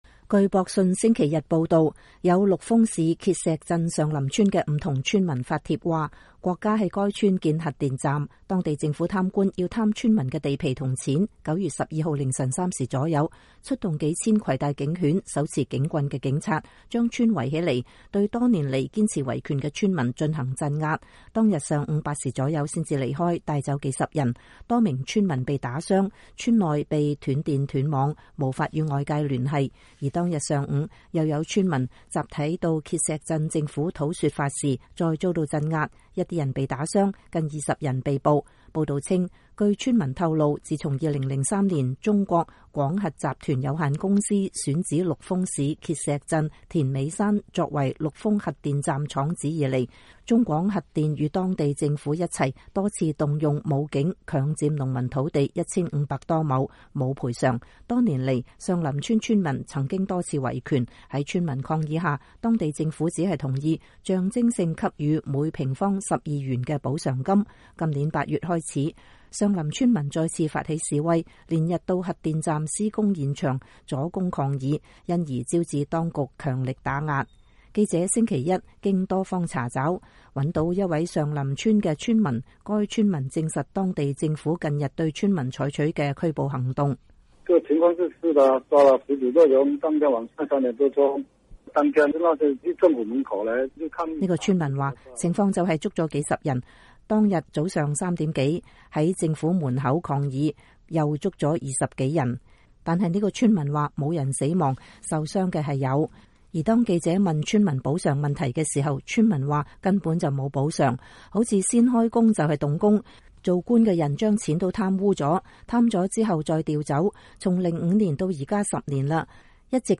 記者星期一經多方查找，找到一位上林村的村民。